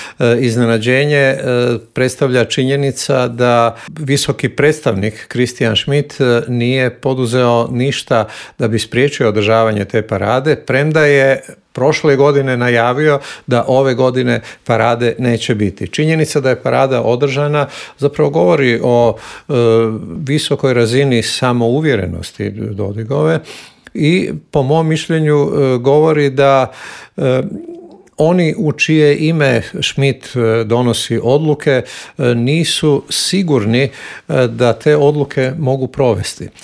Zašto su izbori na Tajvanu prijetnja svjetskom miru i zašto su izgledi za okončanja rata u Ukrajini ove godine mali, pitanja su na koje smo odgovore tražili u intervuju Media servisa s analitičarom Božom Kovačevićem.